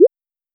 recharge_capsule_3.wav